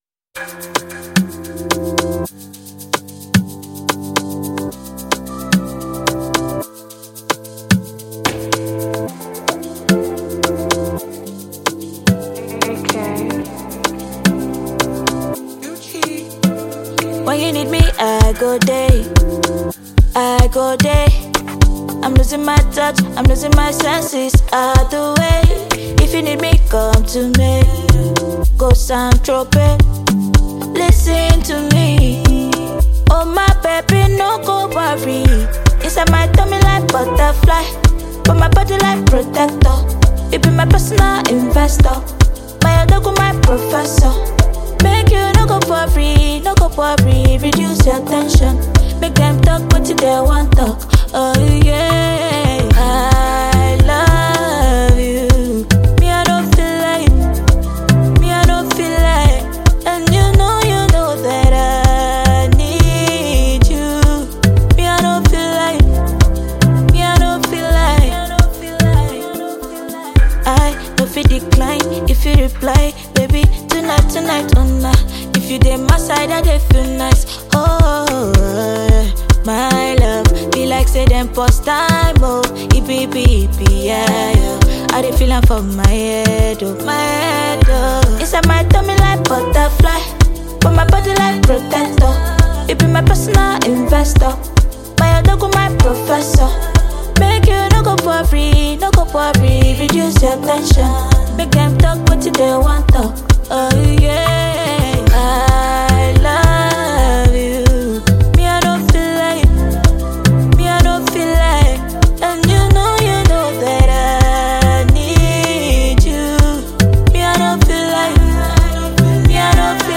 The song gives you a smooth mix of afrobeats and pop
smooth vocals flowing easily over the musical backing